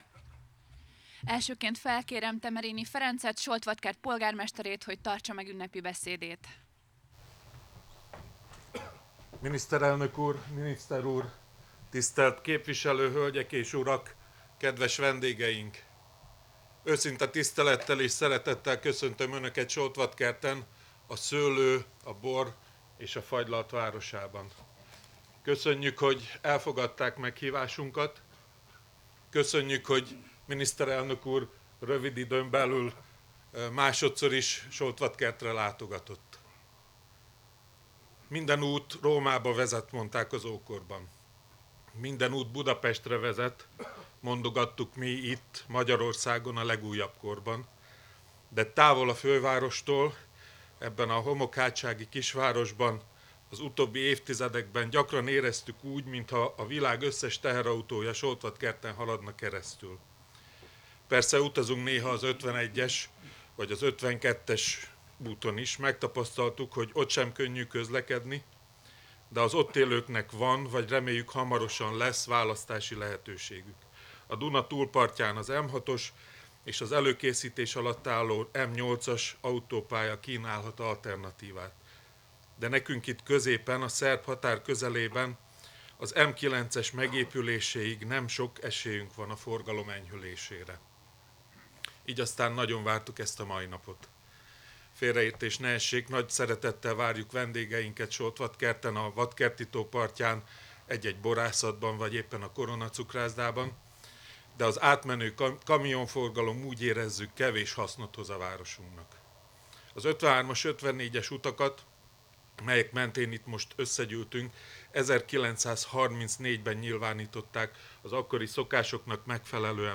Az átadó ünnepség alkalmából beszédet mondott Temerini Ferenc, Soltvadkert város polgármestere, Font Sándor, valamint Orbán Viktor is.